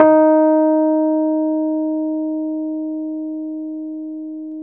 RHODES-EB3.wav